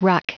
Prononciation du mot ruck en anglais (fichier audio)
Prononciation du mot : ruck